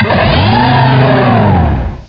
cry_not_samurott.aif